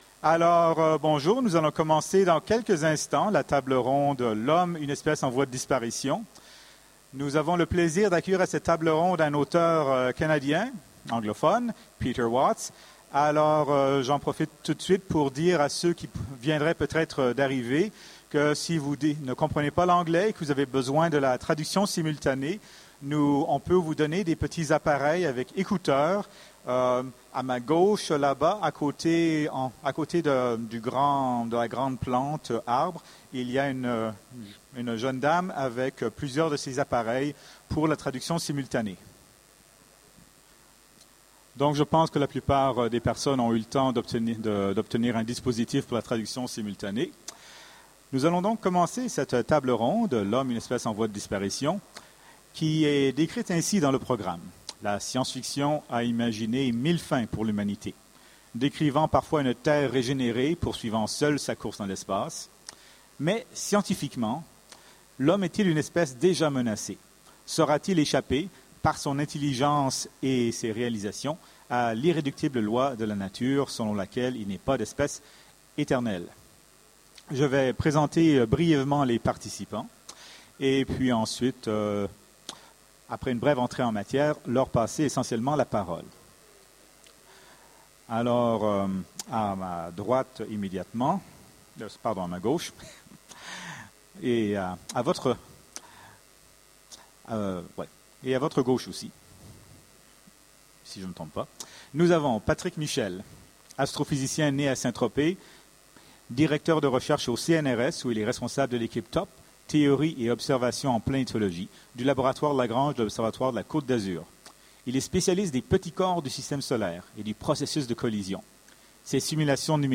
Utopiales 13 : Conférence L'homme est-il une espèce en voie de disparition ?